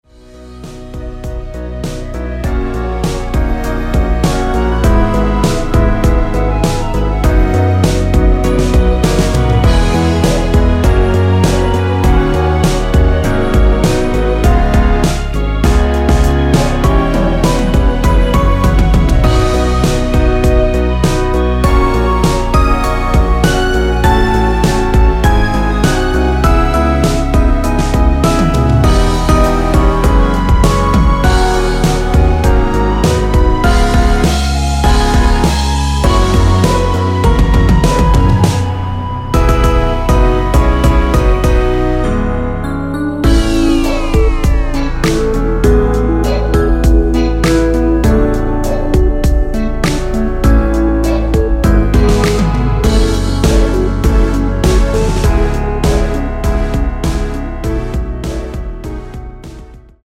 원키에서(-2)내린 멜로디 포함된 MR입니다.(미리듣기 확인)
앞부분30초, 뒷부분30초씩 편집해서 올려 드리고 있습니다.
중간에 음이 끈어지고 다시 나오는 이유는